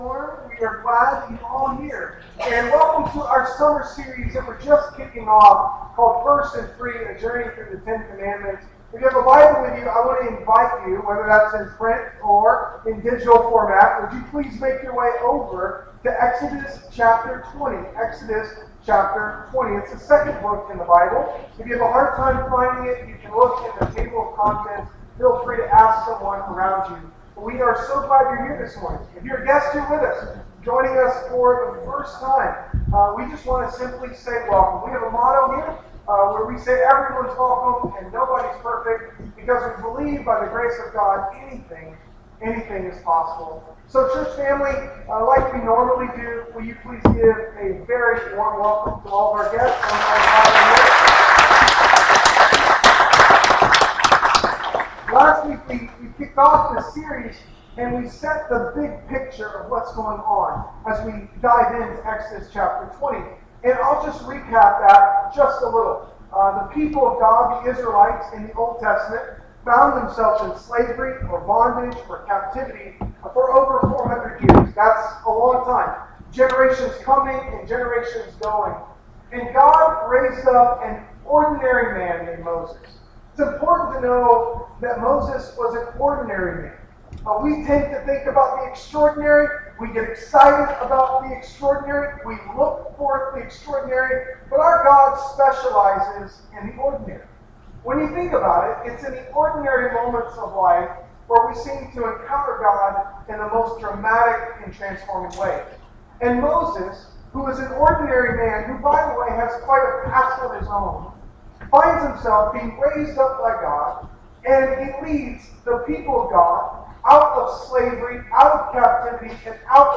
***We apologize for the technical issues recording this sermon. The ten commandments were given to help the people of God learn what it means to put God first and live in freedom. They were not given to limit people, but to liberate people.